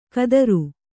♪ kadaru